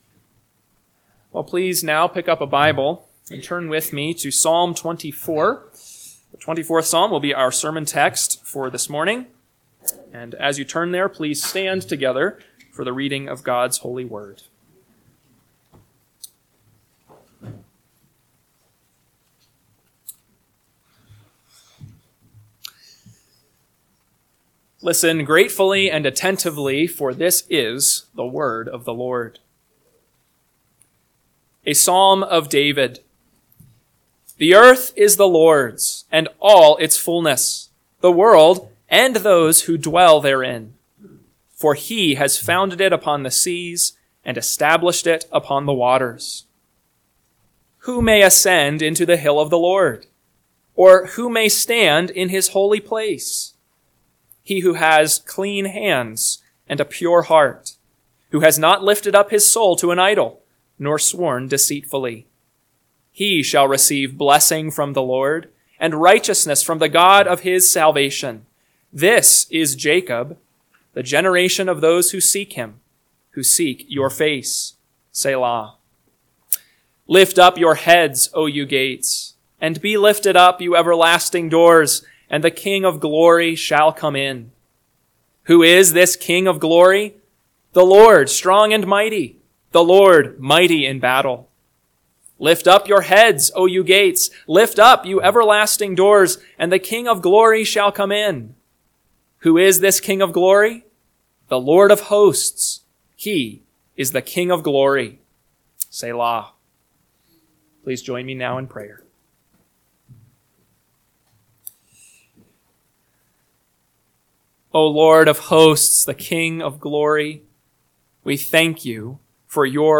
AM Sermon – 6/29/2025 – Psalm 24 – Northwoods Sermons